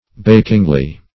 bakingly - definition of bakingly - synonyms, pronunciation, spelling from Free Dictionary Search Result for " bakingly" : The Collaborative International Dictionary of English v.0.48: Bakingly \Bak"ing*ly\, adv. In a hot or baking manner.